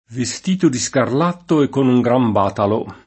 veSt&to di Skarl#tto e kkon uj gram b#talo] (Boccaccio); con uno tabarro e co’ batoli dinanzi in forma da parere più tosto medico che cavaliere [kon uno tab#rro e kko b#toli din#nZi in f1rma da ppar%re pLu tt0Sto m$diko ke kkavalL$re] (Sacchetti)